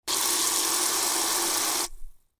دانلود صدای آب 82 از ساعد نیوز با لینک مستقیم و کیفیت بالا
جلوه های صوتی